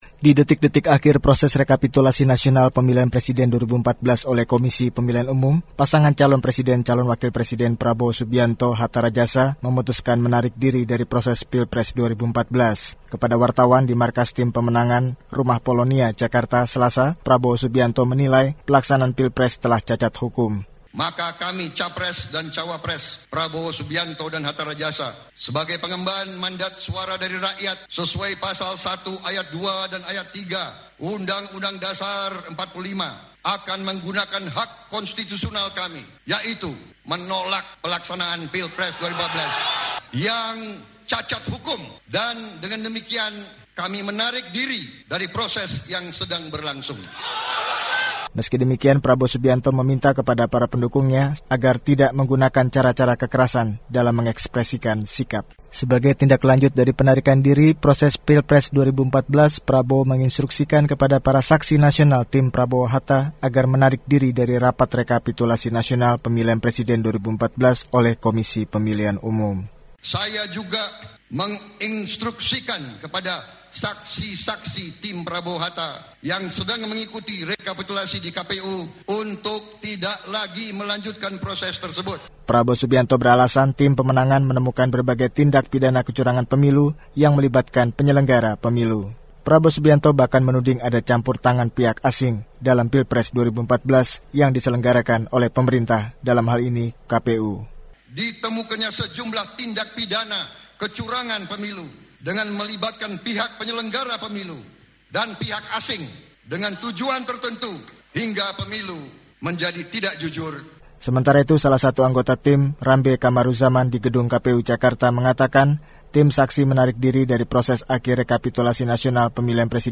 Di detik-detik akhir proses rekapitulasi nasional pemilihan presiden 2014 oleh Komisi Pemilihan Umum, pasangan calon presiden calon wakil presiden Prabowo Subianto - Hatta Rajasa memutuskan menarik diri dari proses Pilpres 2014. Kepada wartawan di markas tim pemenangan Rumah Polonia Jakarta Selasa (22/7), Prabowo Subianto menilai pelaksanaan pilpres telah cacat hukum.